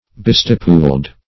Bistipuled \Bi*stip"uled\, a.